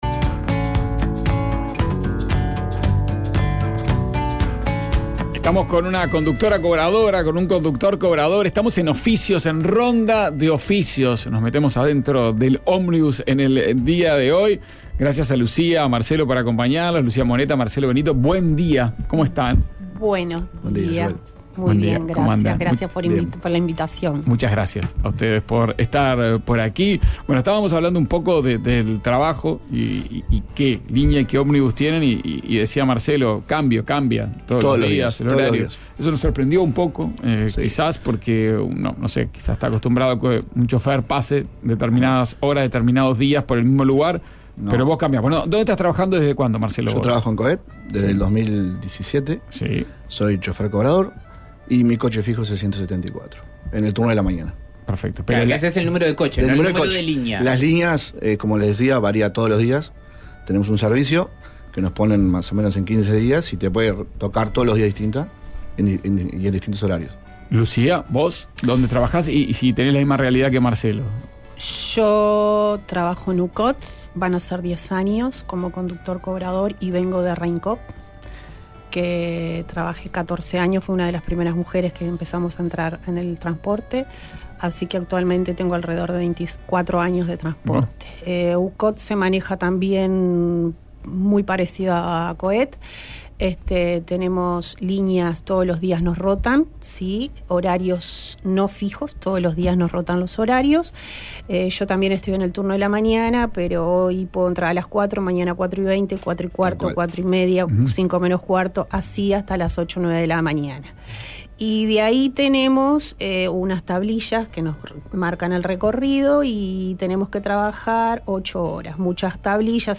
El oficio de chofer de ómnibus en la voz de una conductora y un conductor.